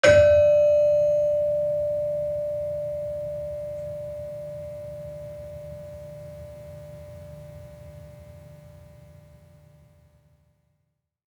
Gender-3-D4-f.wav